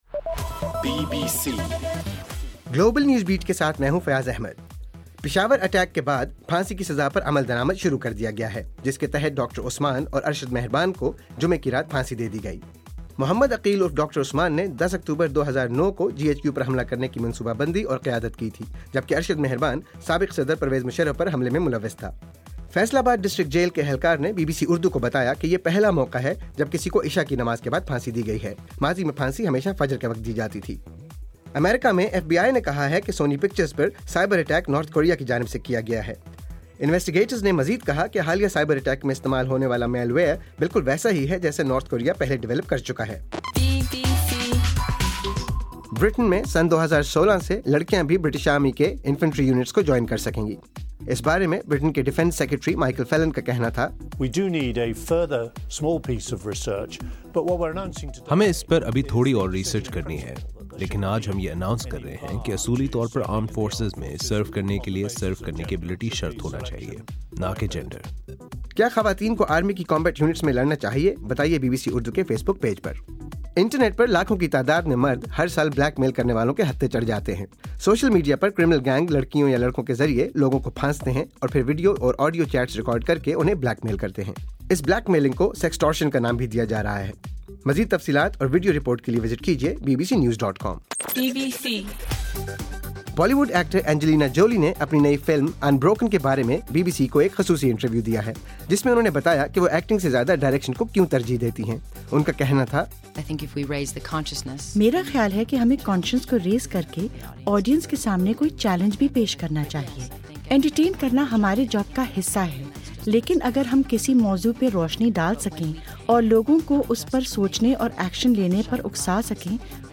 دسمبر 19: رات 11 بجے کا گلوبل نیوز بیٹ بُلیٹن